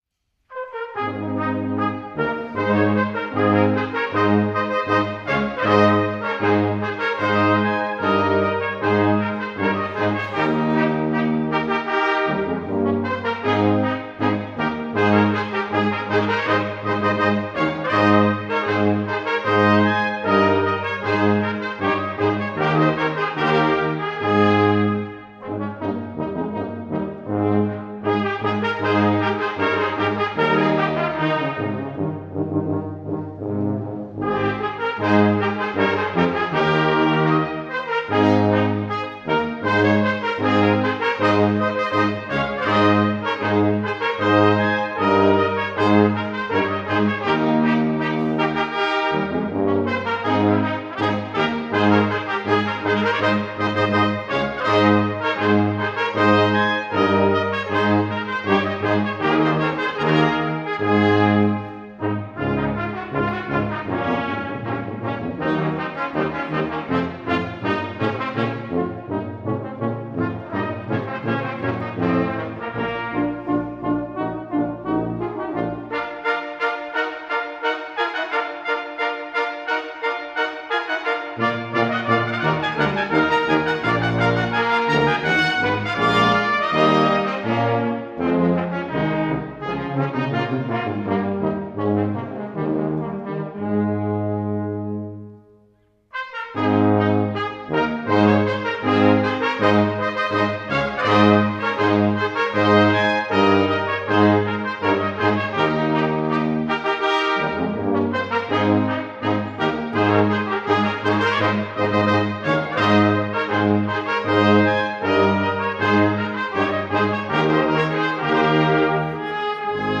Liveaufnahme der Königsfanfare
beim Kirchenkonzert 2006 in Immendorf